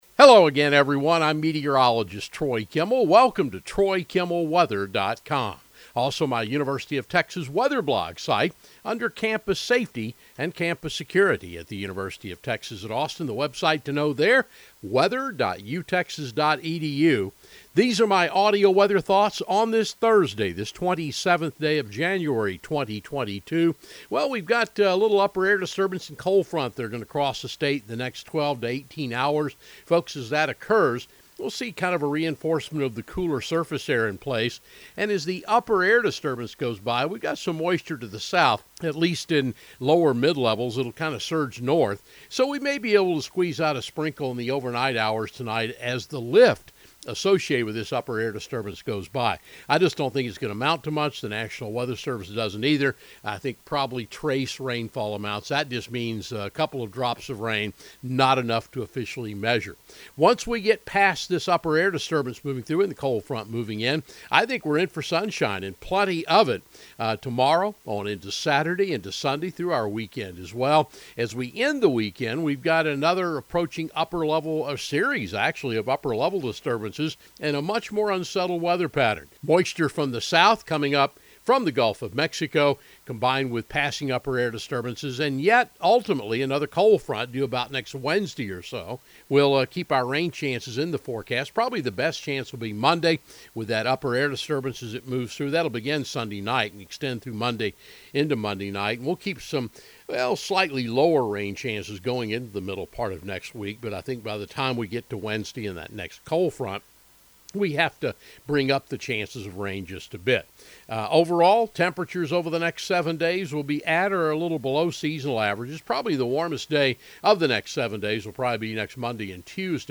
Audio Weather Webcast